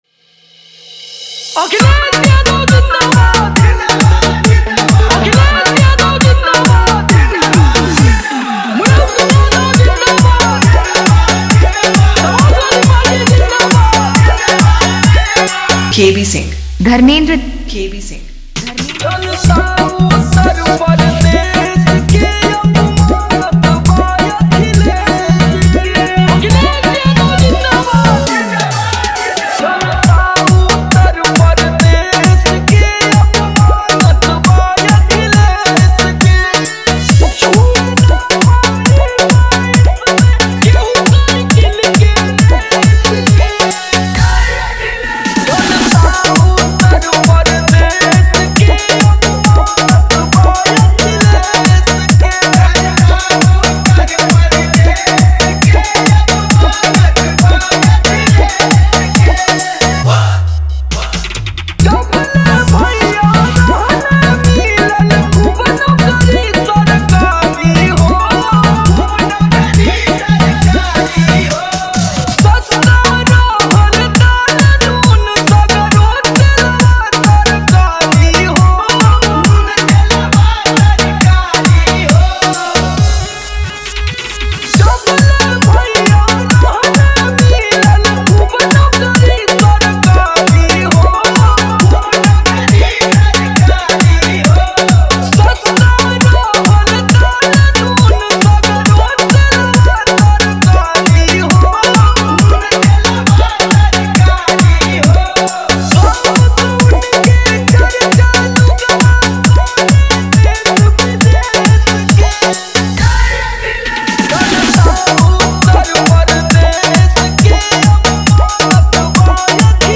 Party Song Dj Remix